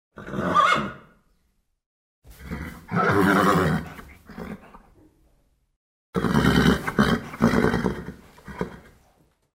Звуки ржания лошади
Лошадь усмехается